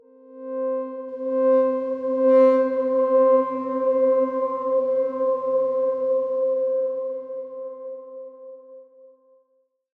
X_Darkswarm-C4-mf.wav